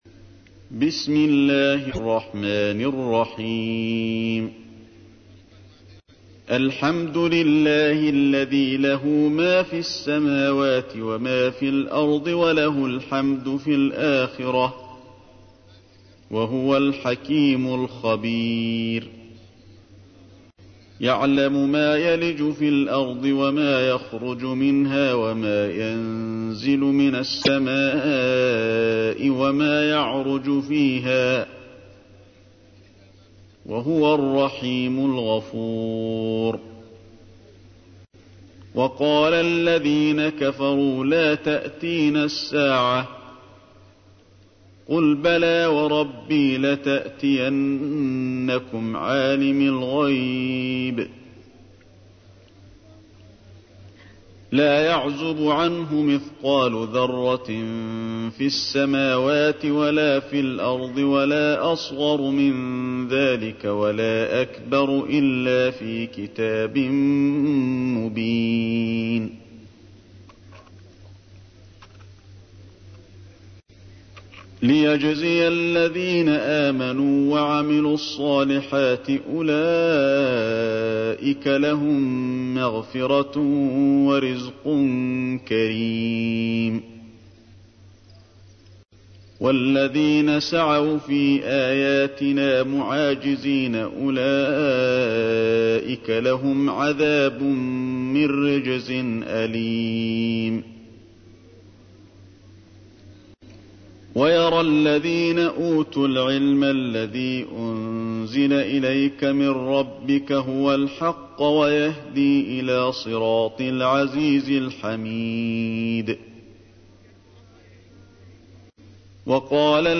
تحميل : 34. سورة سبأ / القارئ علي الحذيفي / القرآن الكريم / موقع يا حسين